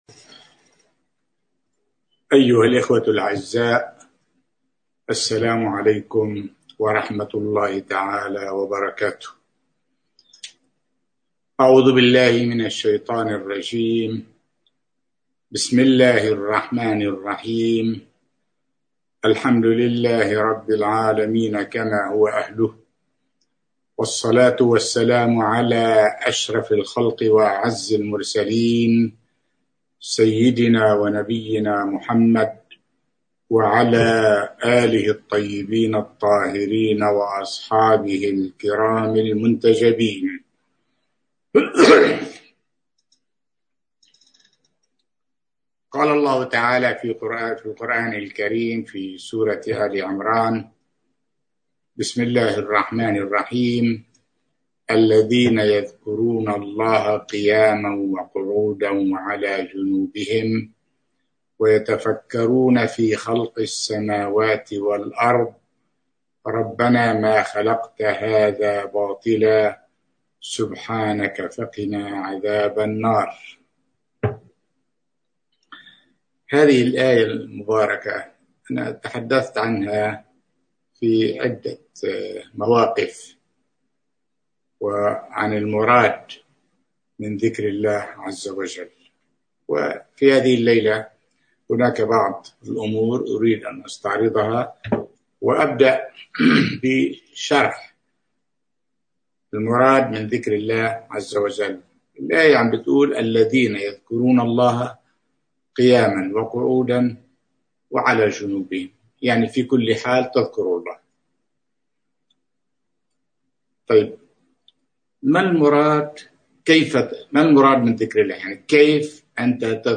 محاضرة
ألقاها في السنغال